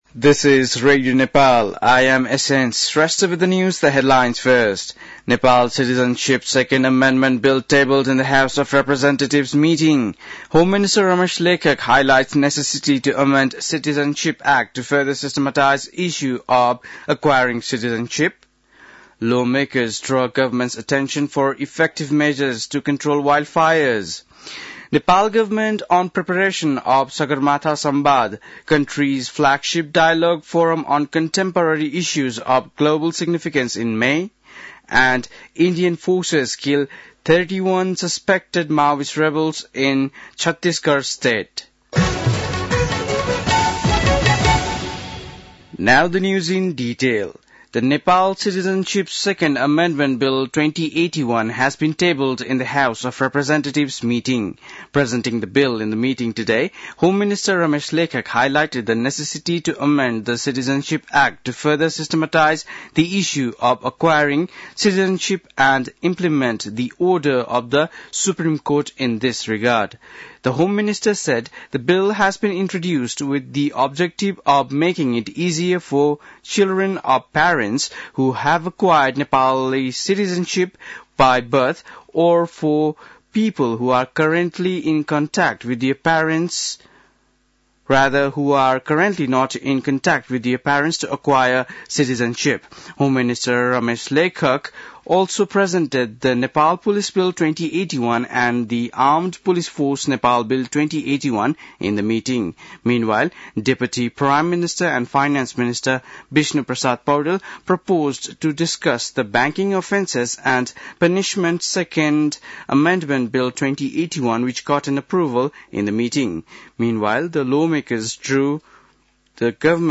बेलुकी ८ बजेको अङ्ग्रेजी समाचार : २८ माघ , २०८१
8-pm-english-news.mp3